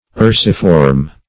Search Result for " ursiform" : The Collaborative International Dictionary of English v.0.48: Ursiform \Ur"si*form\, a. [L. ursus, ursa, a bear + -form.] Having the shape of a bear.